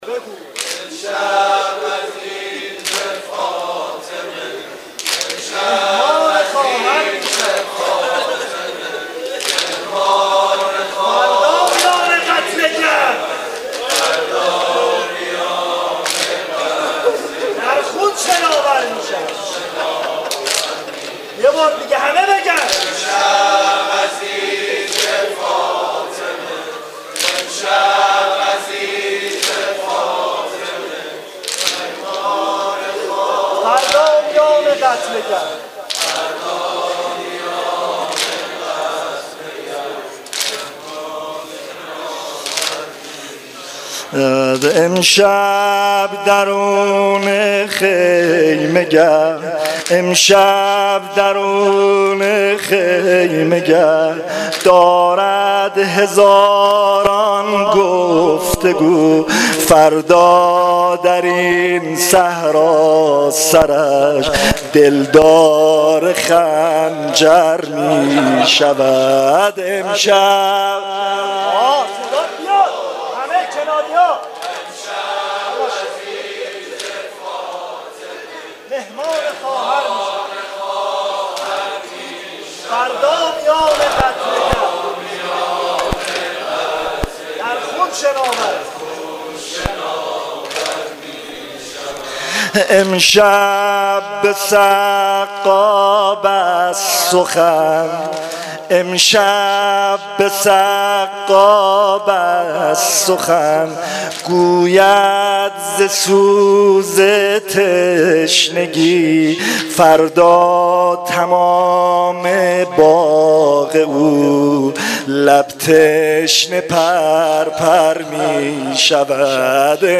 نوحه شب دهم محرم(عاشورای حسینی)
Noheh-Shabe-10-moharram94.mp3